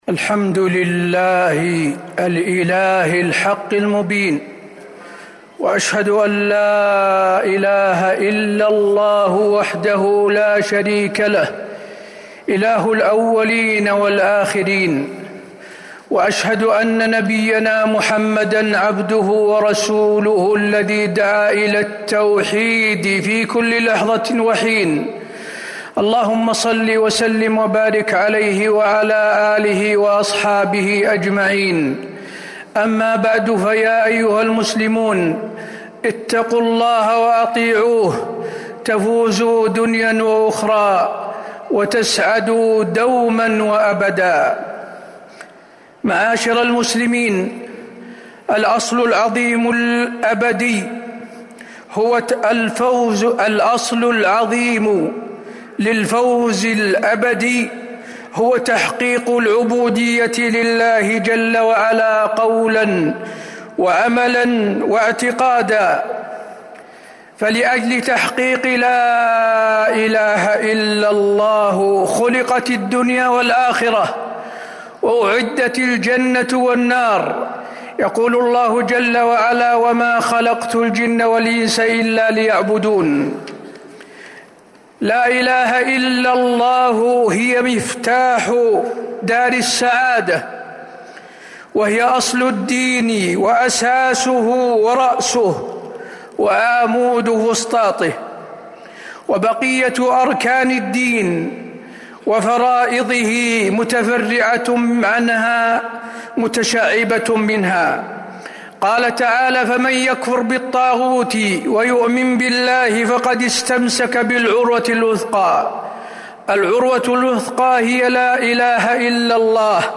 المدينة: واجب المسلمين والعلماء نحو لا إله إلا الله - حسين بن عبد العزيز آل الشيخ (صوت - جودة عالية